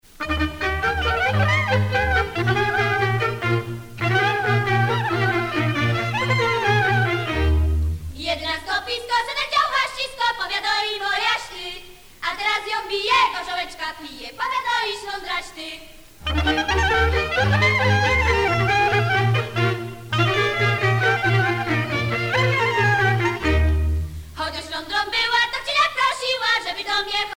Mémoires et Patrimoines vivants - RaddO est une base de données d'archives iconographiques et sonores.
Noble danse à deux couples
Pièce musicale inédite